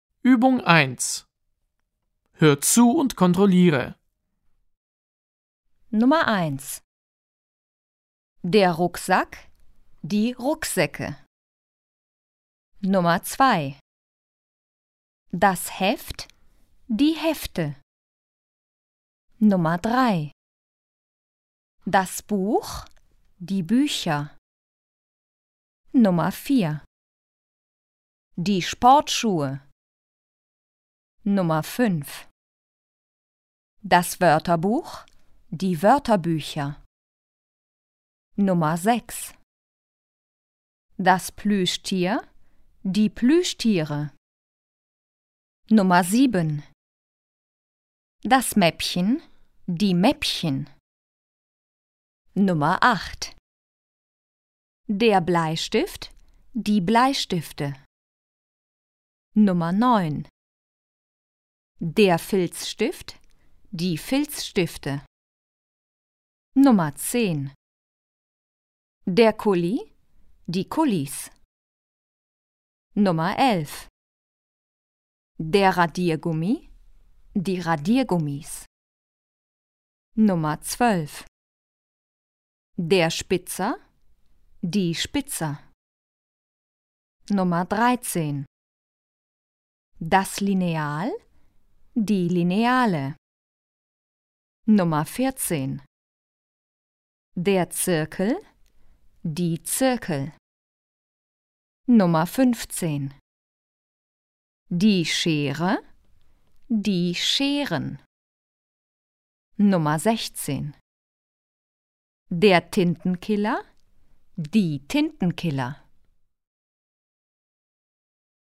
Dołączyłam Wam plik z nagraniem, na którym usłyszycie nazwy przyborów z zad. 1 w podr. s. 90 odpowiednio ponumerowane, co pomoże w tłumaczeniu.